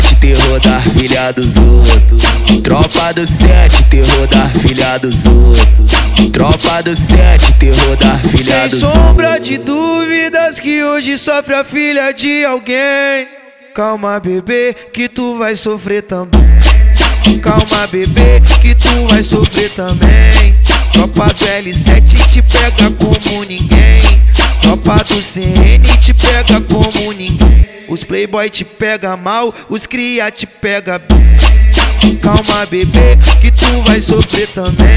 Жирный бас-клава и хлопковые гитары
Baile Funk Brazilian
Жанр: Фанк